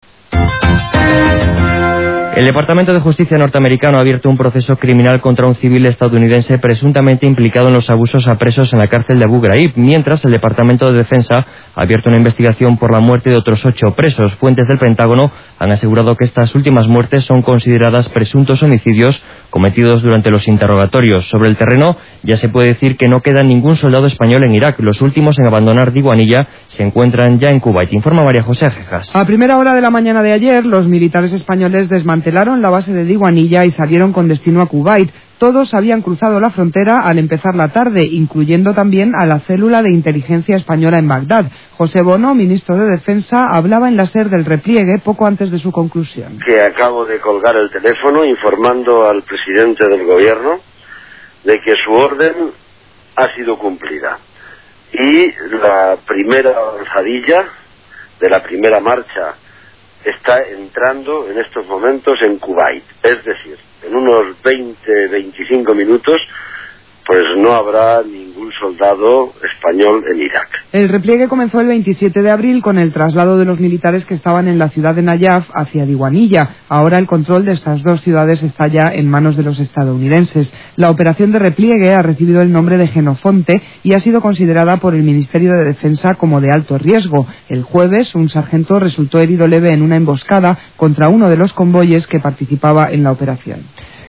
Per començar, escoltarem tres fragments de diferents butlletins de notícies de ràdio. Corresponen al dia 21 de maig de 2004 i fan referència a la retirada de les tropes espanyoles de l’Iraq: